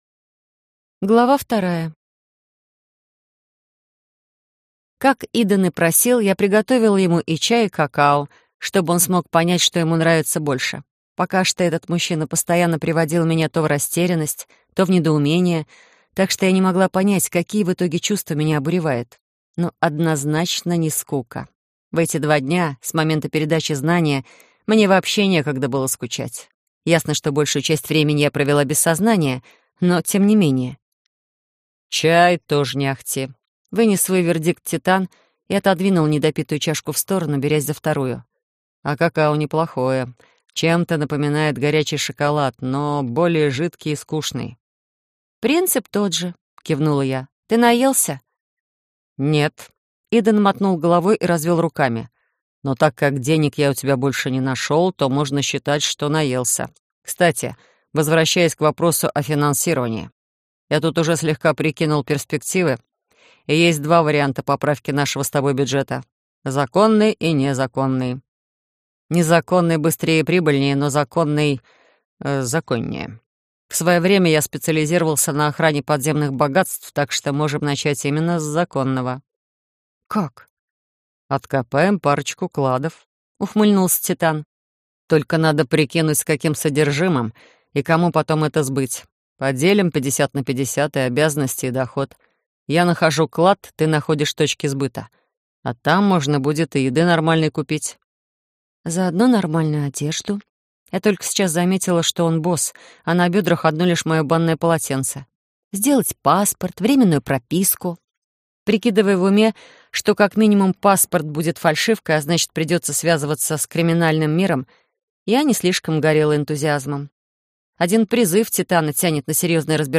Аудиокнига Преступный симбиоз - купить, скачать и слушать онлайн | КнигоПоиск